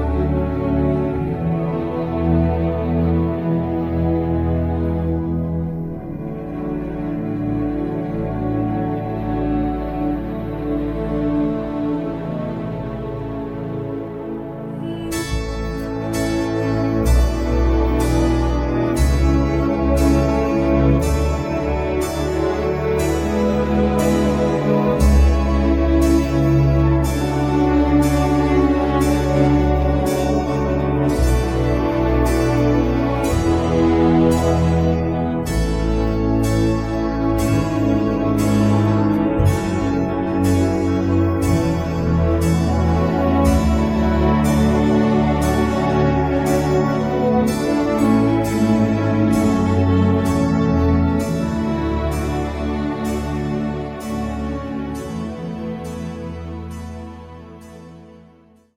음정 -1키